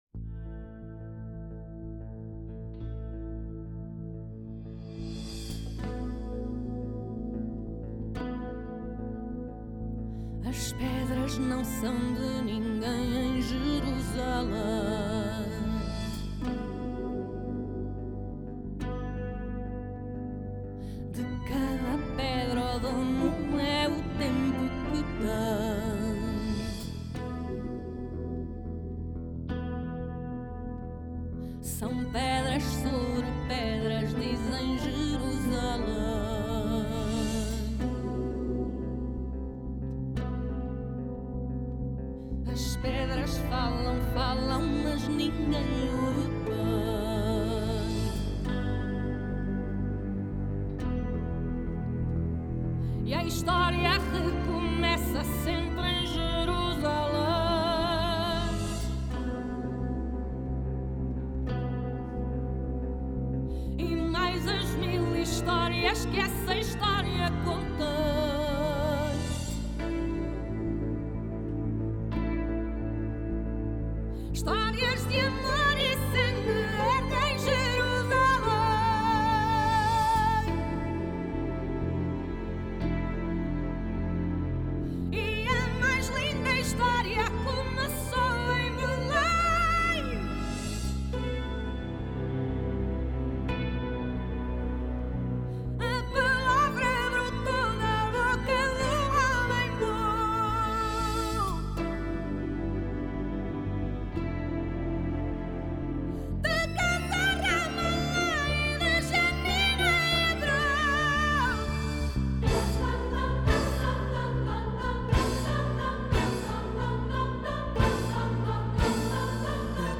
Genre: Score
編寫全新的管弦樂曲